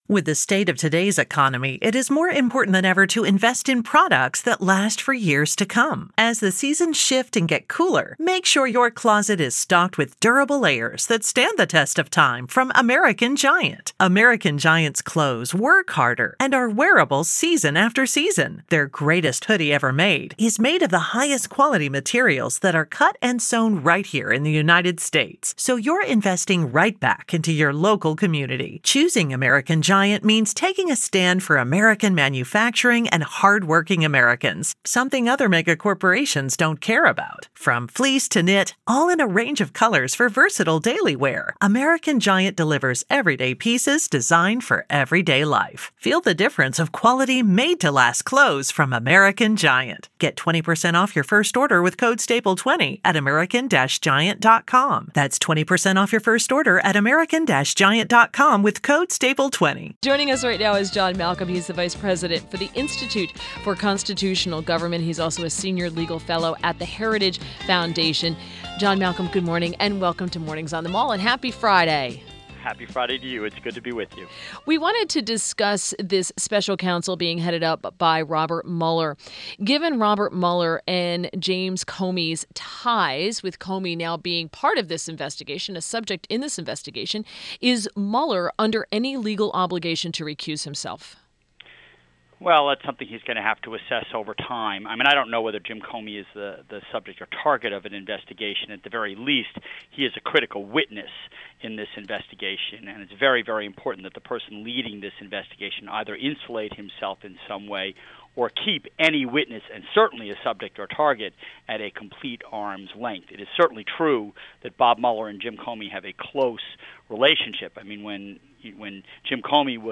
Mornings on the Mall / WMAL Interview